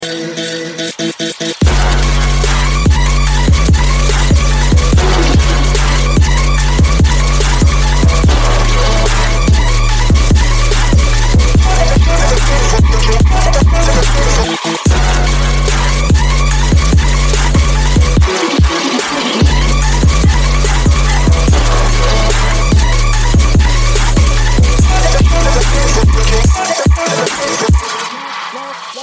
Громкие Рингтоны С Басами » # Рингтоны Без Слов
Рингтоны Электроника